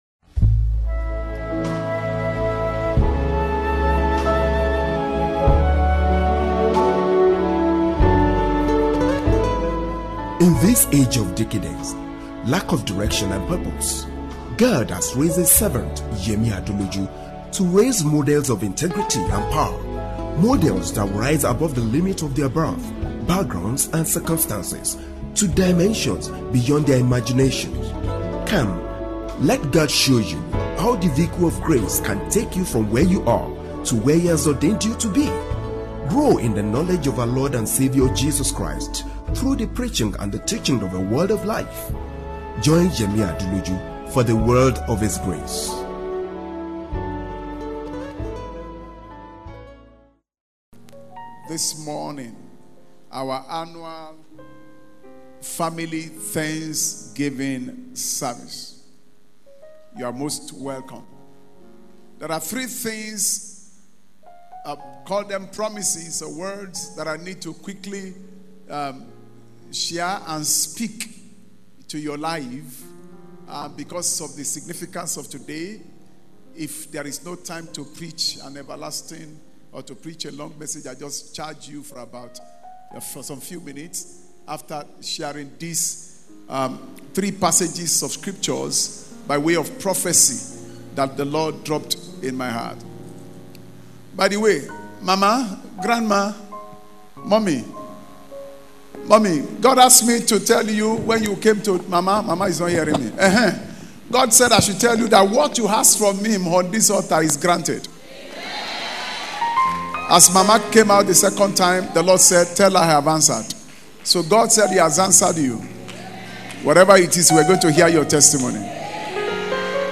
Annual Family Thanksgiving Service - Lighthouse International Christian Centre